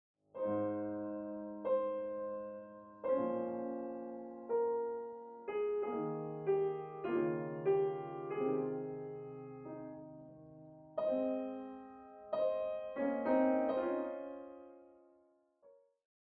piano
2/2 – 32 bars